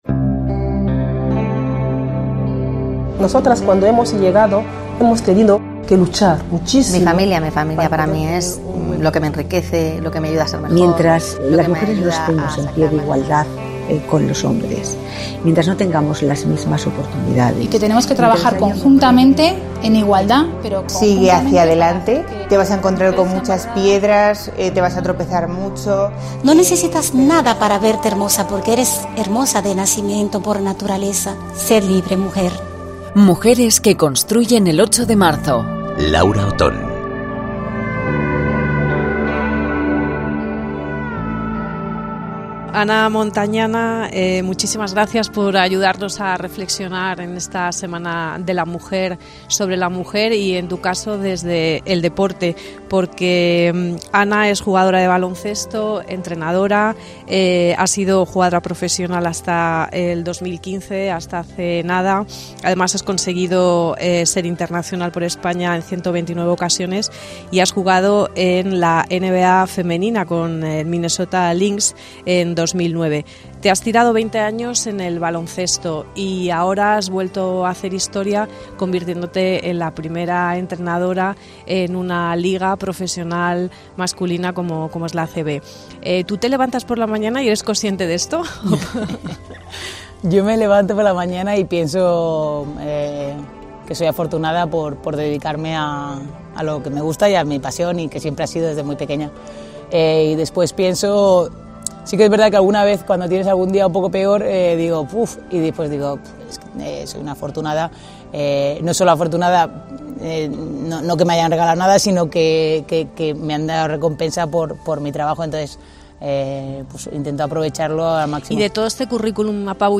Un balón suelto le da en la nariz justo antes de empezar la entrevista mientras bromea con sus compañeros.